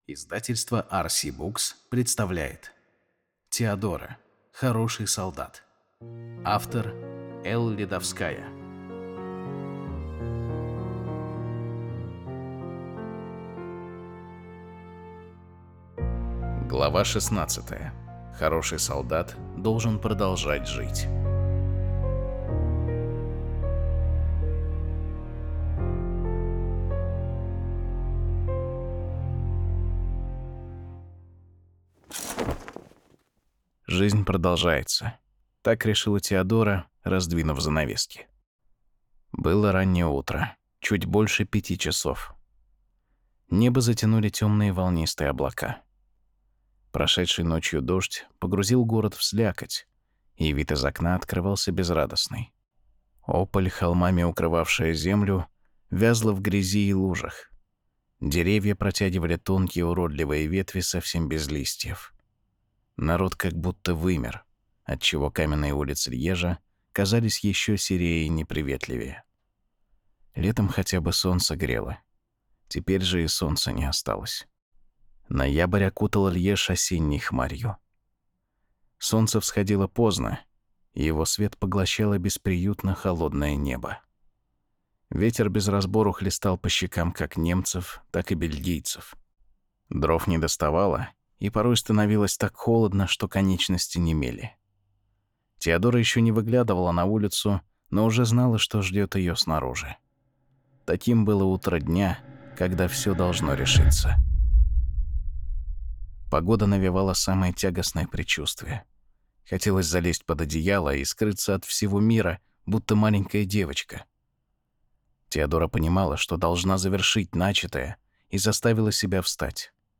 Аудиокнига (аудиоспектакль). Многоголосая версия.